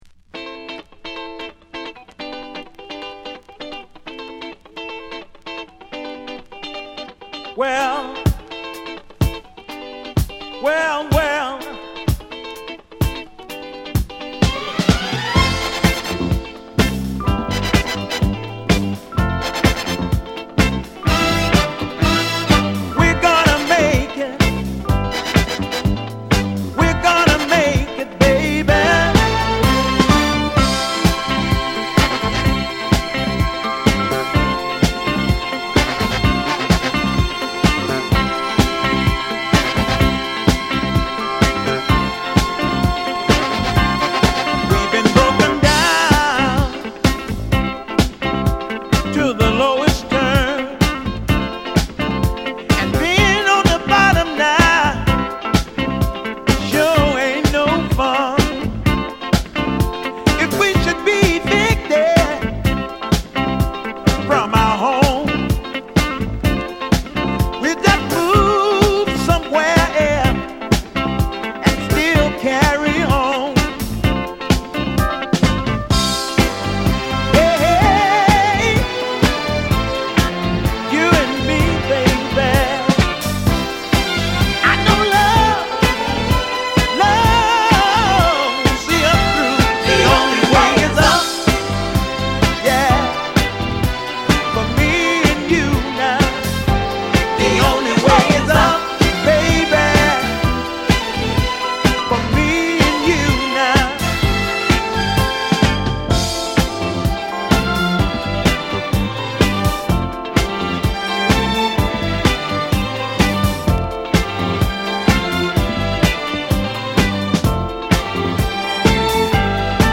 ブレイクビーツ